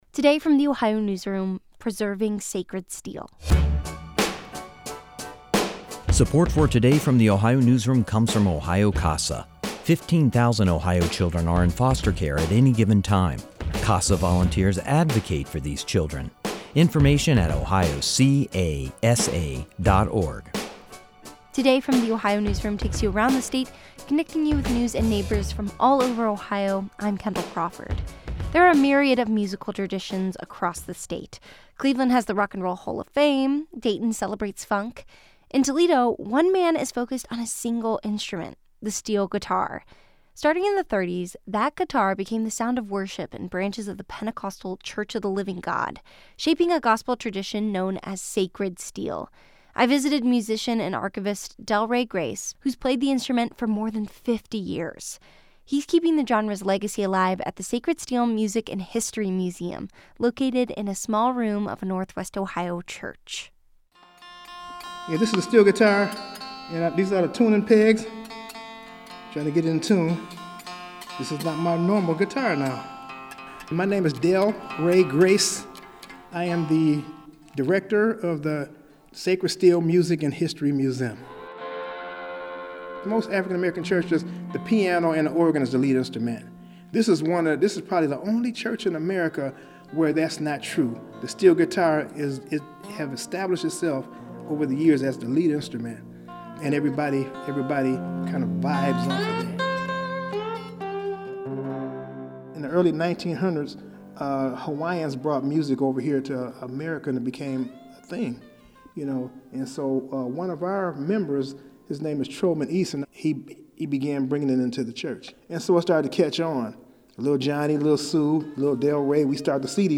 In one hand, he held a steel bar across the frets, allowing the guitar to slide effortlessly between notes, giving it its signature wail.